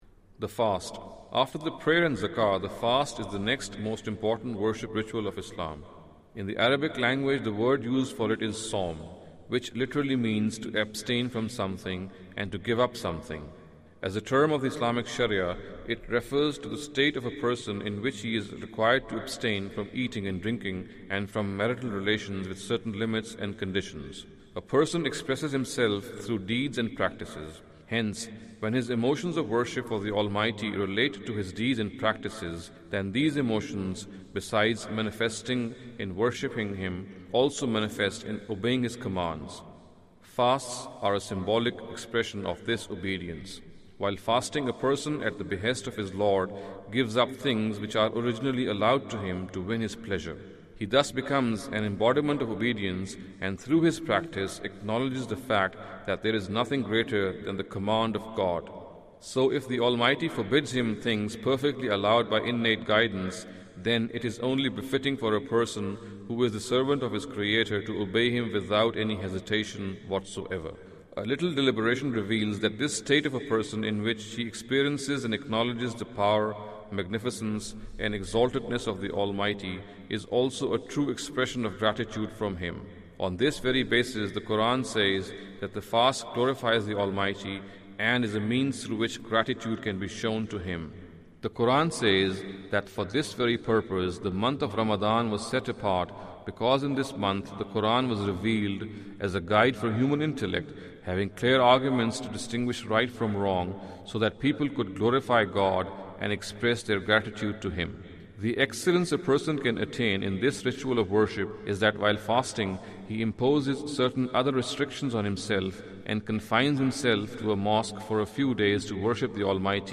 Category: Audio Books / Islam: A Concise Introduction /
Audio book of English translation of Javed Ahmad Ghamidi's book "Islam a Concise Intro".